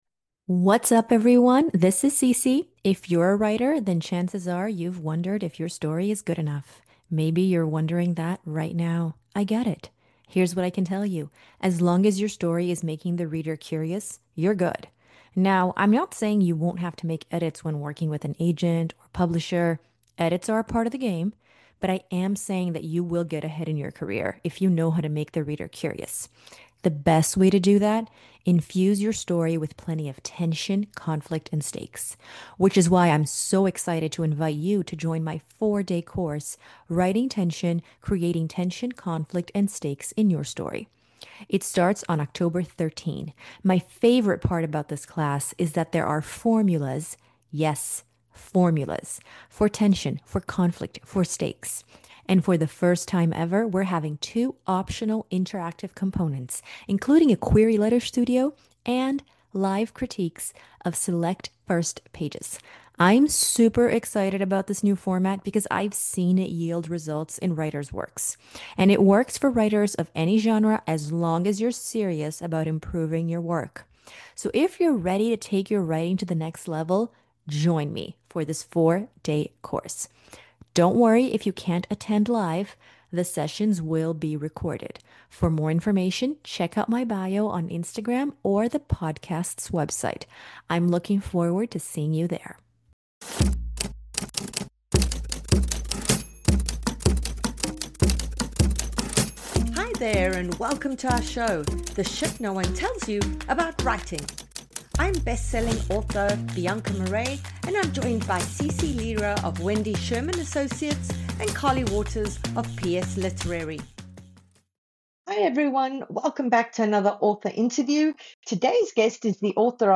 In this engaging author interview